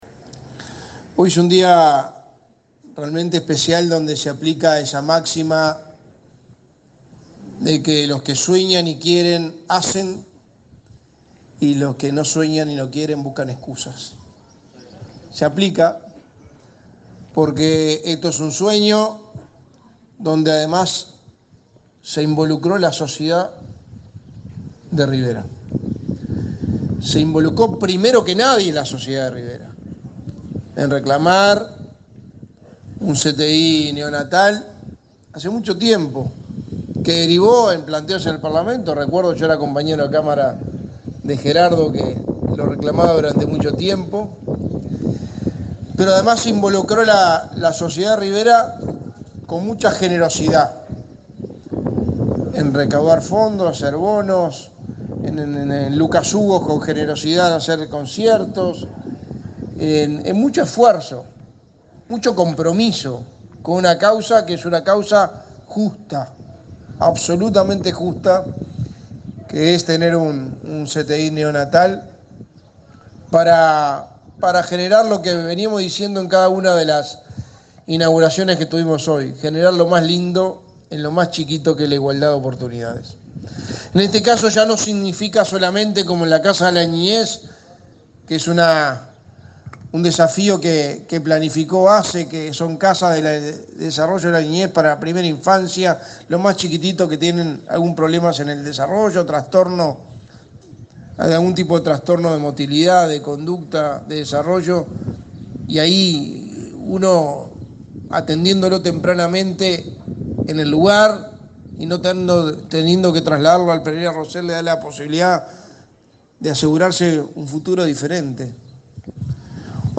Palabras del secretario de Presidencia, Álvaro Delgado, en el Hospital de Rivera
Este miércoles 1.°, el secretario de Presidencia, Álvaro Delgado, participó en Rivera, de la inauguración de la nursery neonatal de cuidados